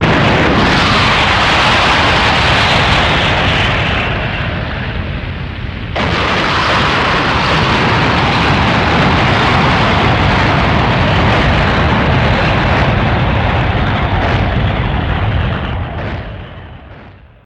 Звуки гейзеров
Грохот гейзера, выстреливающего воду